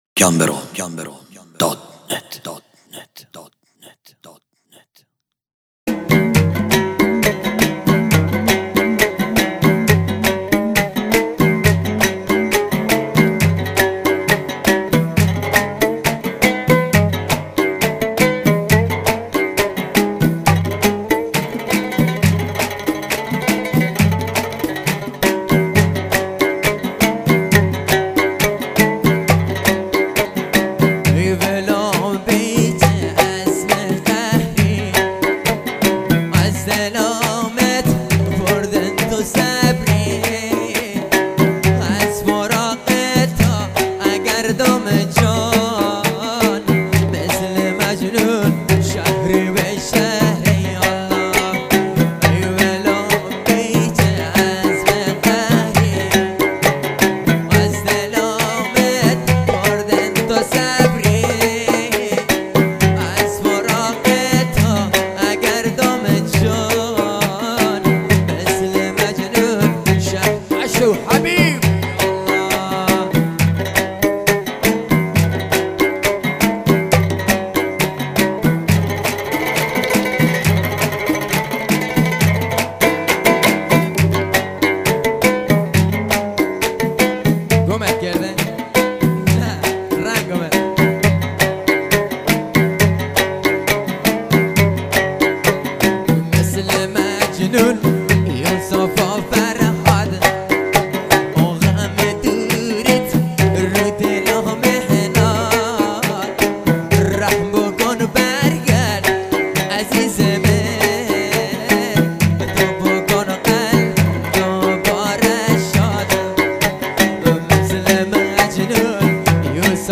دانلود آهنگ بستکی